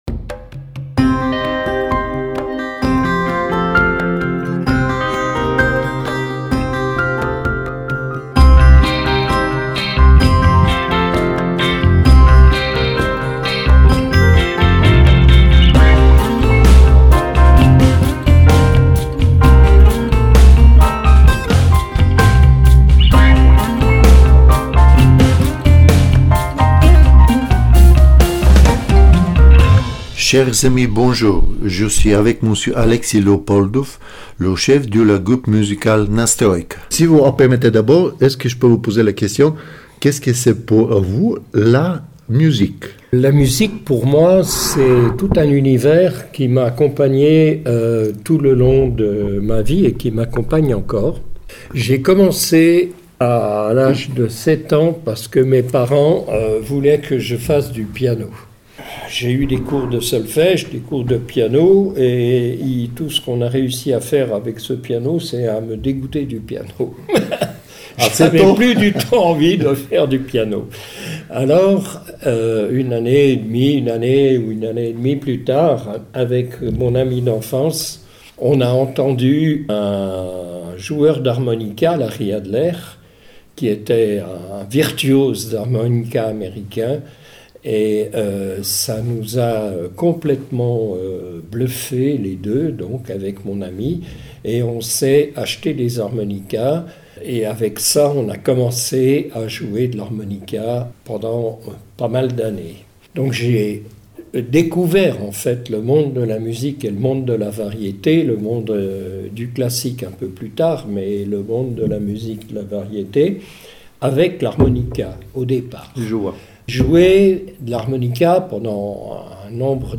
Au programme, cette équipe motivée on mis sur pied deux soirées de mixture rock avec six groupes incroyable qui ce produiront à la Villa Tacchini! Venez découvrir ça dans cet interview chaleureux et convivial!